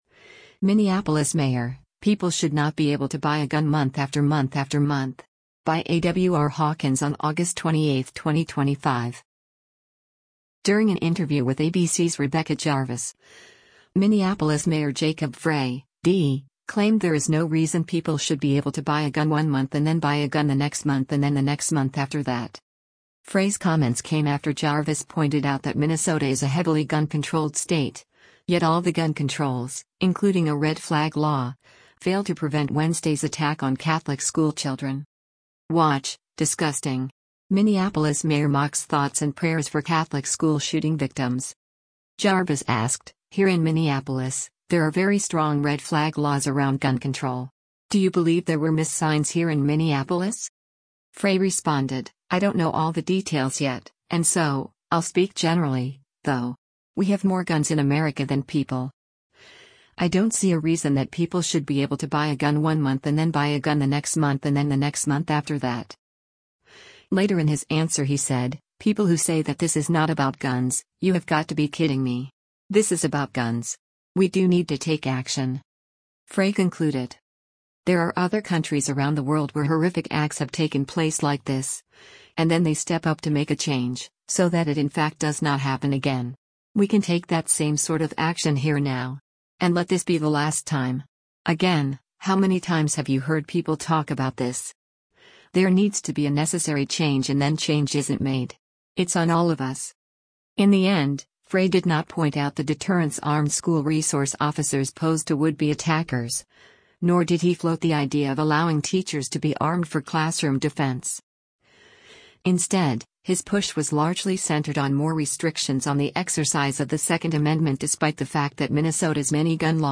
Minneapolis Mayor Jacob Frey speaks to the media following a mass shooting at Annunciation
During an interview with ABC’s Rebecca Jarvis, Minneapolis Mayor Jacob Frey (D) claimed there is no reason people “should be able to buy a gun one month and then buy a gun the next month and then the next month after that.”